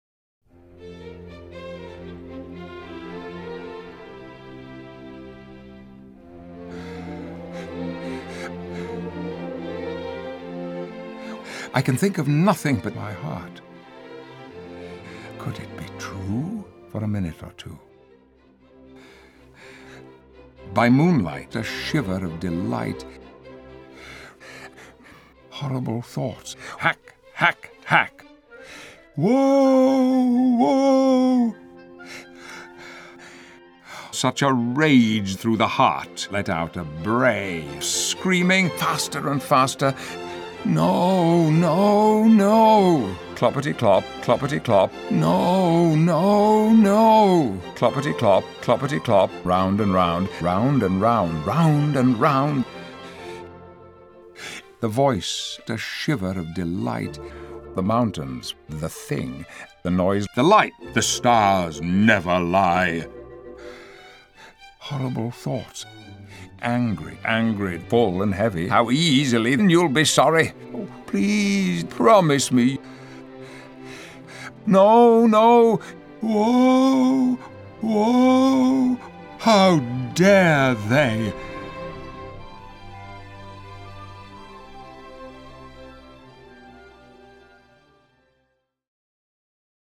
Patrick Stewart reading terrible poetry
Patrick-Stewart-reading-terrible-poetry.mp3